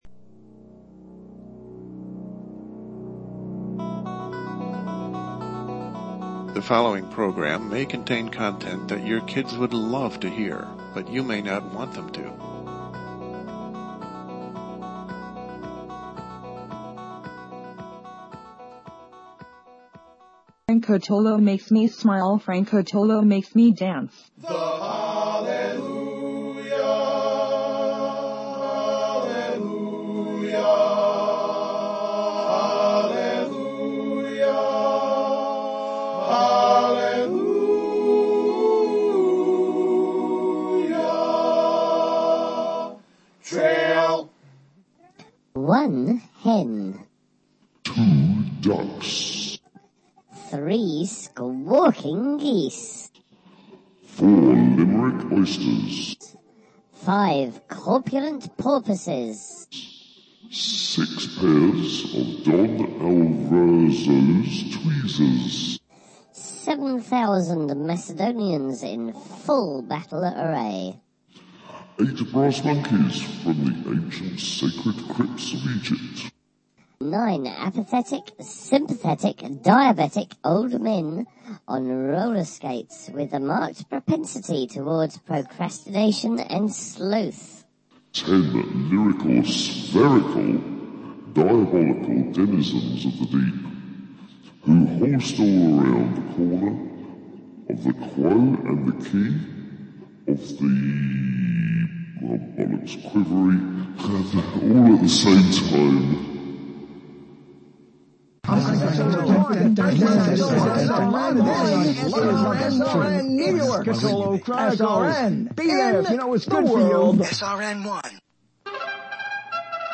LIVE, Thursday, Nov. 1 at 9 p.m. LIVE, with a lot to say about a lot of things that affect you daily, even at this moment and the next. Also, requests granted for segments gone missing.